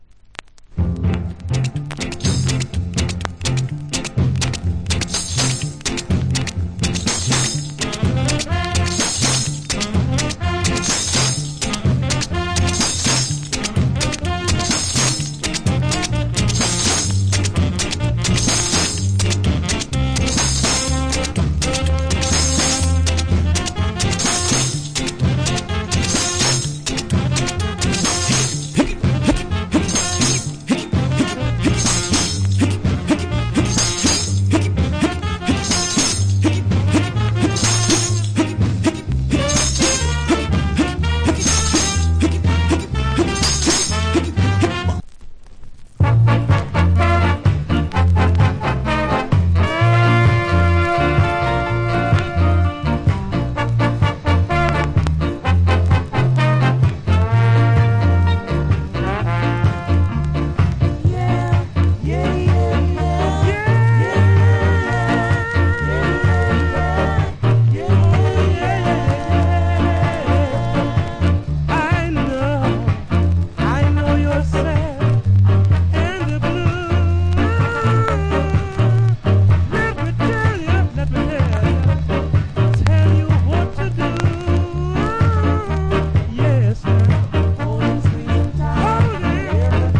Killer Ska Inst.